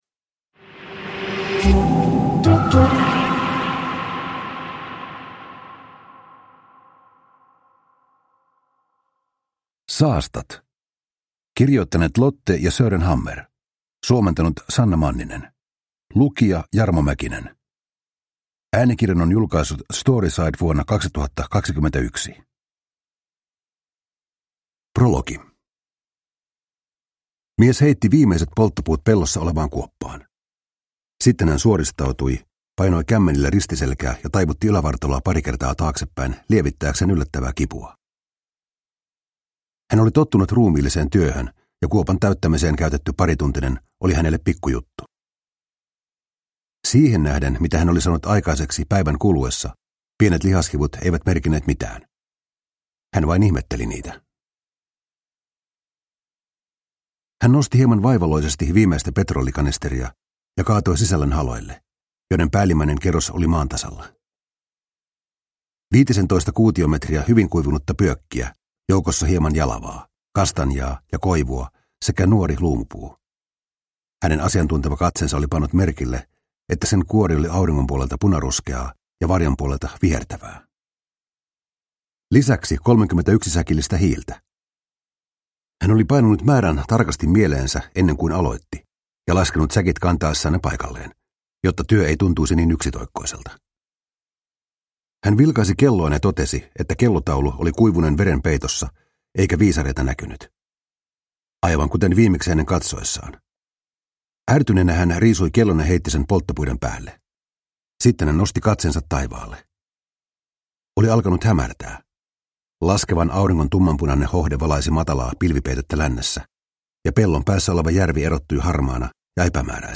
Saastat – Ljudbok – Laddas ner